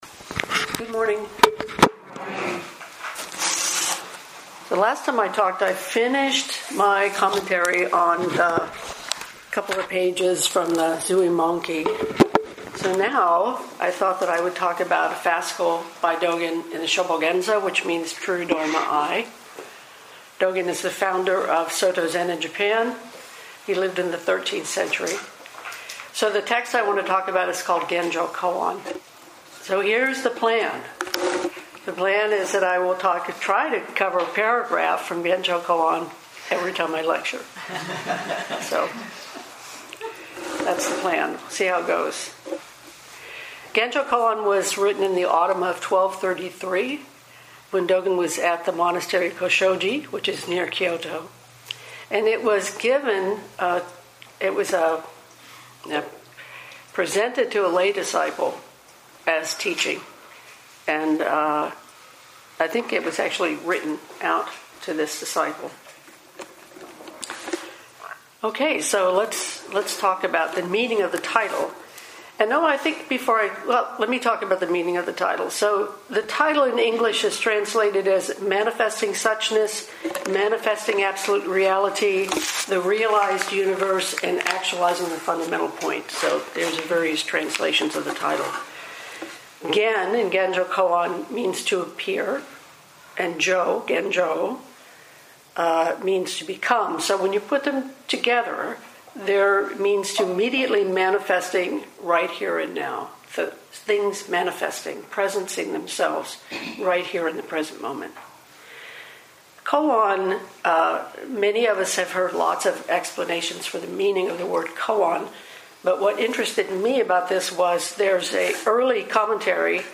Shobogenzo-Genjokoan-talk-1-compressed.mp3